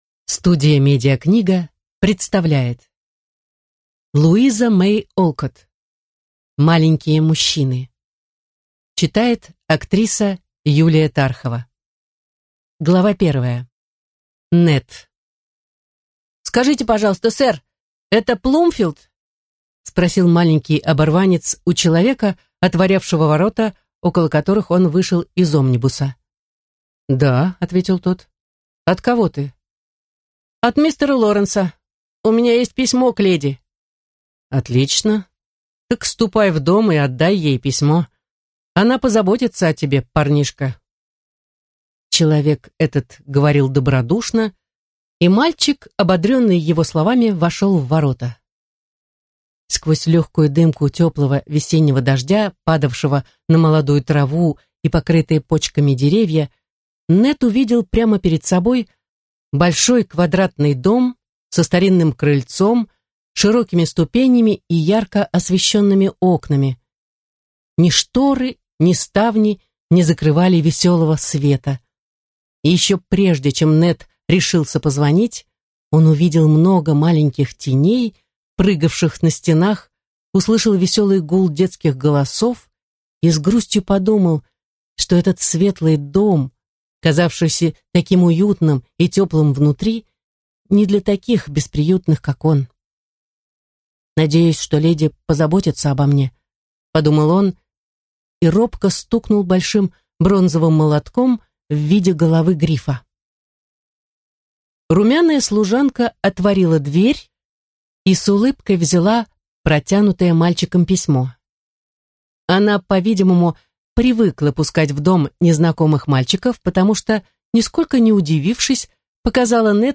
Книга прочитана популярной киноактрисой и известной актрисой дубляжа Юлией Тарховой.